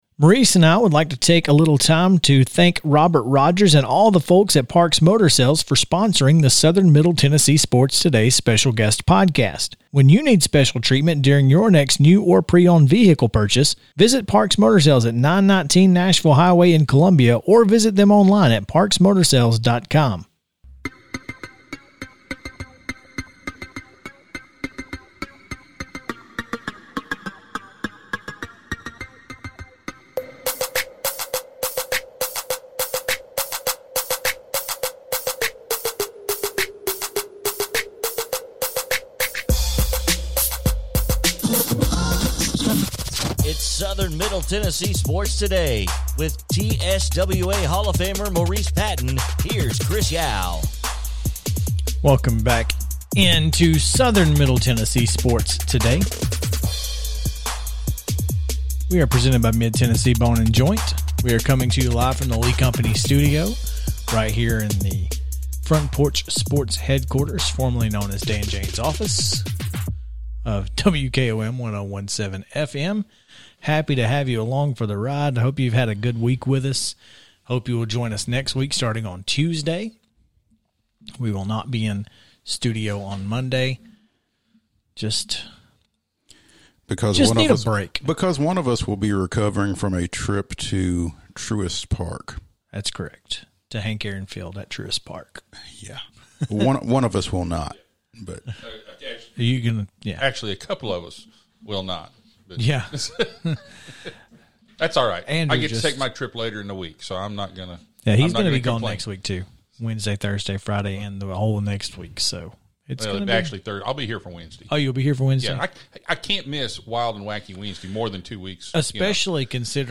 Southern Middle Tennessee Sports: Today's interview